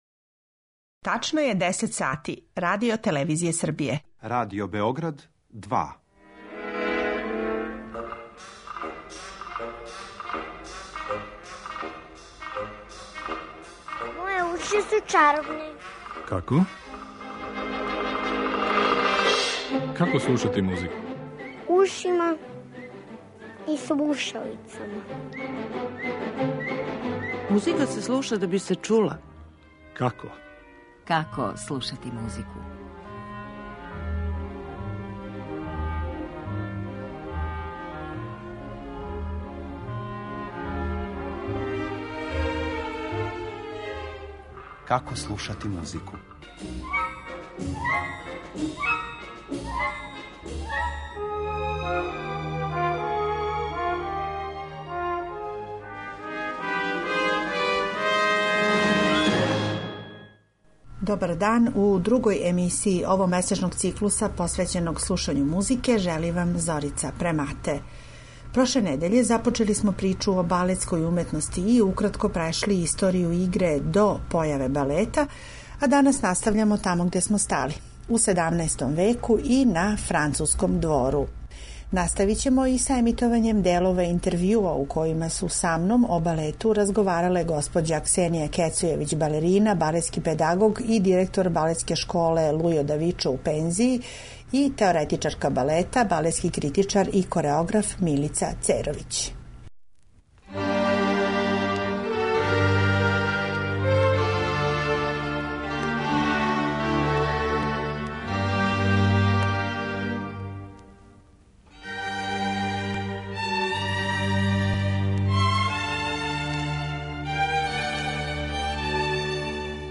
Емитоваћемо и инсерте интервјуа